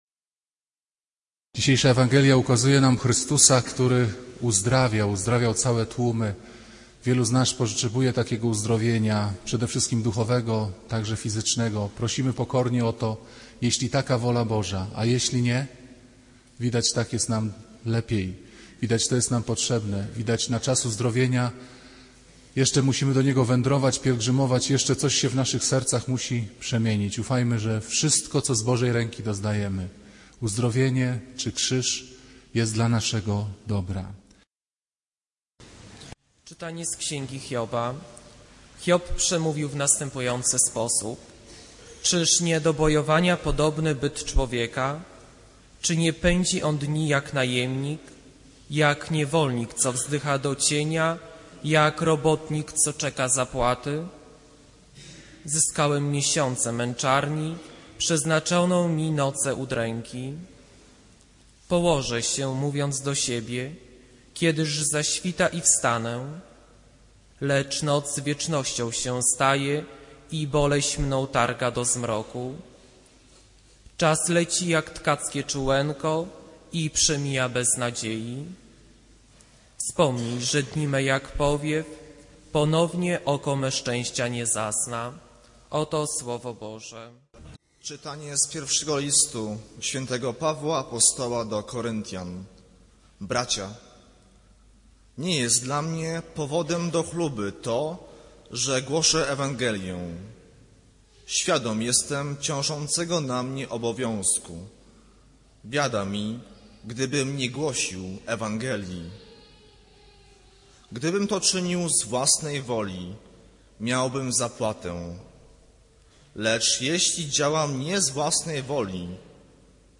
Kazanie z 8 lutego 2009r.
Piotra Pawlukiewicza // niedziela, godzina 15:00, kościół św. Anny w Warszawie « Kazanie z 1 lutego 2009r.